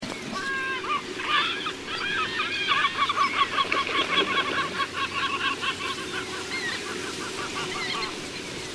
Large Gulls in the Southeastern Urals
Call recording 2